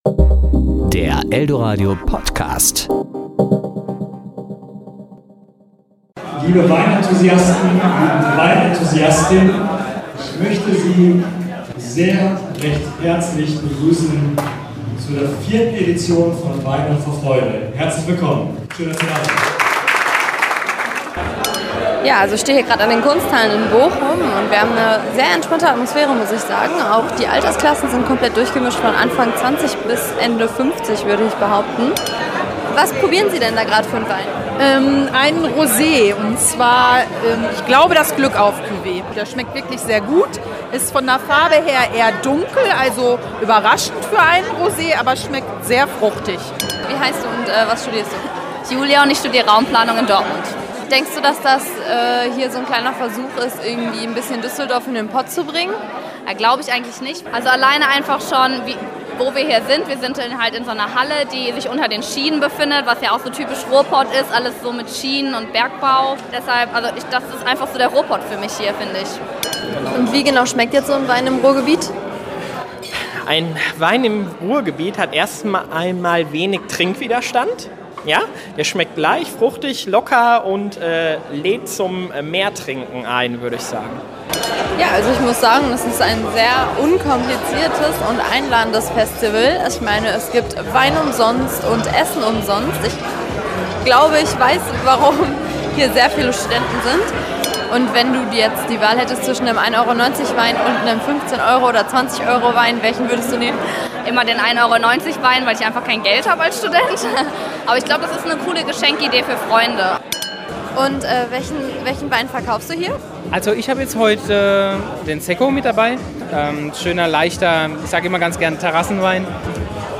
Wir haben uns auf die Suche nach dem Geschmack des Ruhrgebiets gemacht. Und zwar auf dem Weinfestival in Bochum "Weine vor Freude".